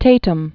(tātəm), Arthur Known as “Art.” 1909?-1956.